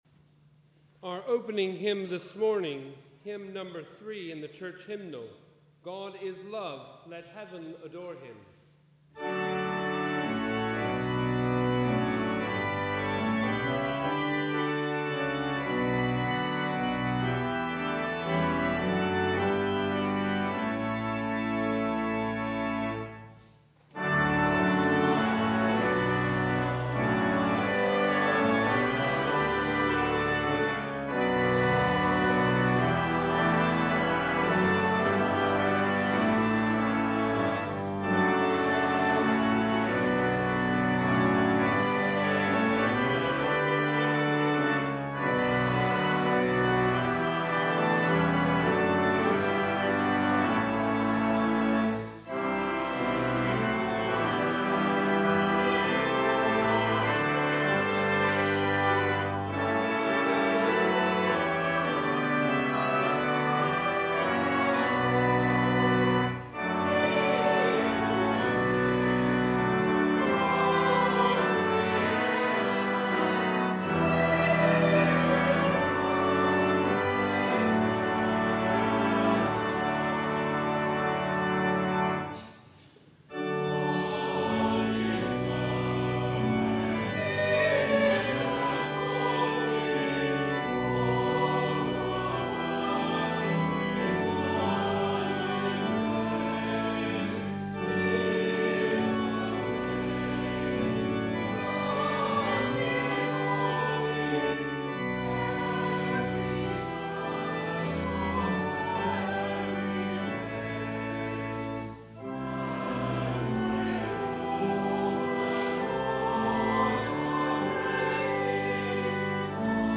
Welcome to our service of Holy Communion on the 8th Sunday after Trinity.